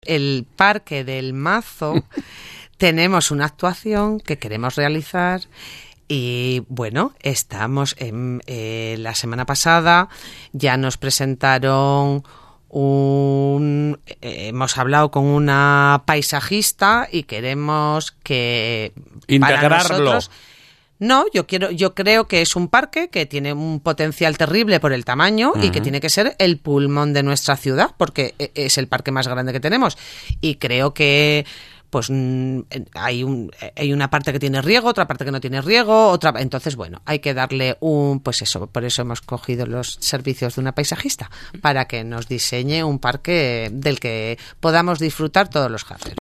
Guadalupe Fernández lo ha dicho en RADIO HARO.